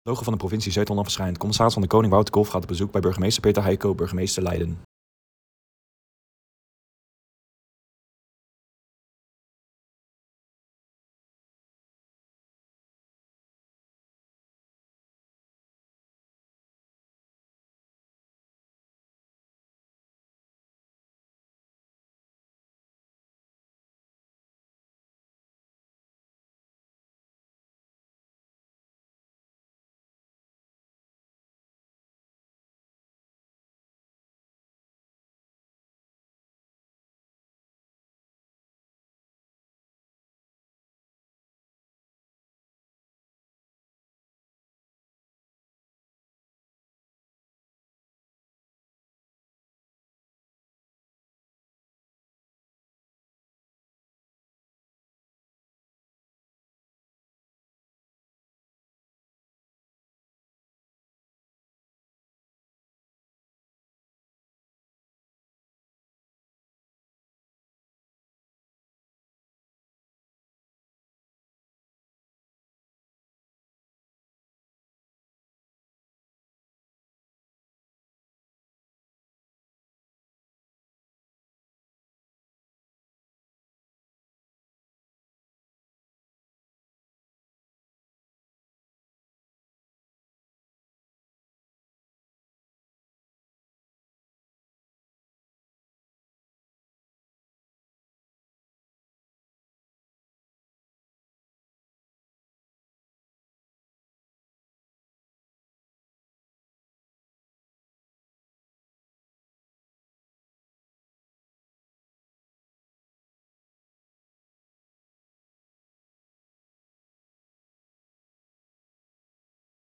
CdK in gesprek met burgemeester Leiden
CdK in gesprek met de burgemeester van Leiden.